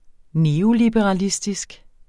Udtale [ -libəʁɑˈlisdisg ]